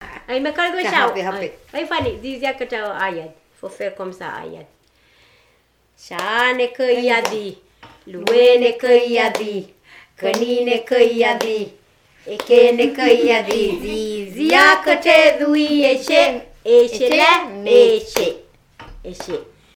Chants enfantins kanaks
Pièce musicale inédite